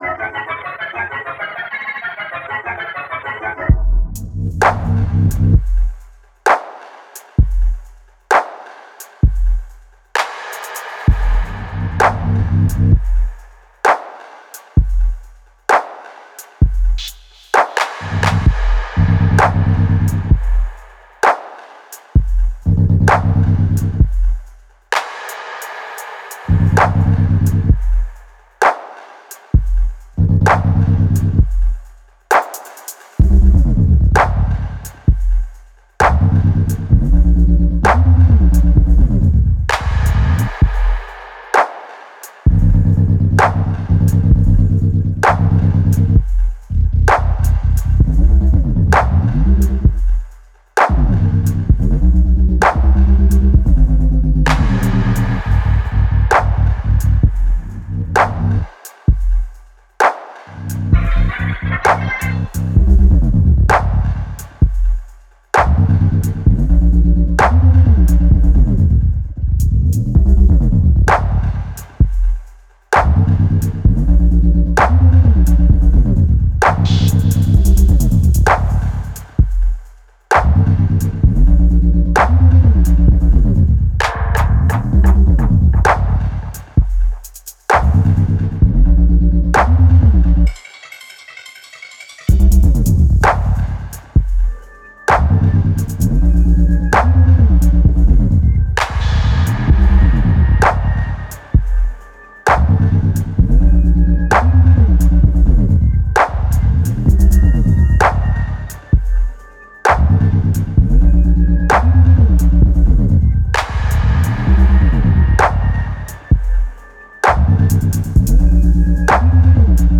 Rather than use samples, all you’ll have to use is an online Chord Generator and a Drum Machine.
Tempo Range: 130bpm exactly Key: C Harmonic Minor